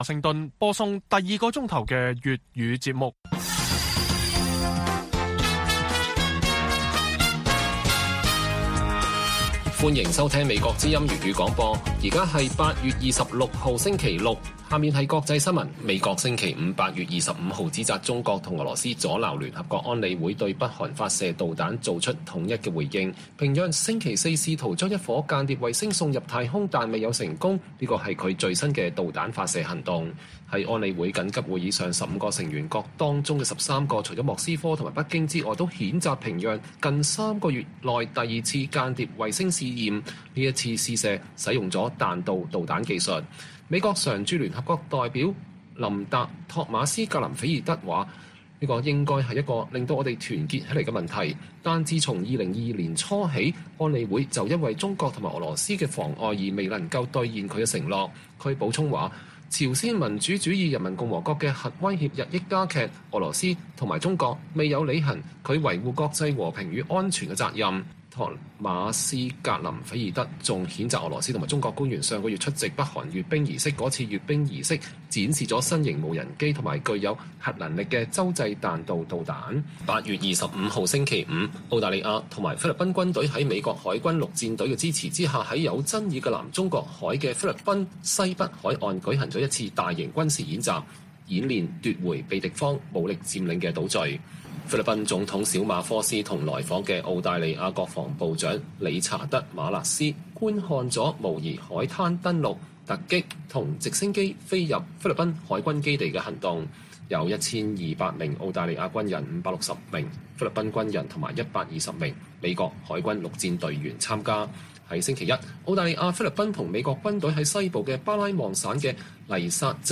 粵語新聞 晚上10-11點 : 英國BN(O)簽證超18萬香港人申請 數字按季穩定上升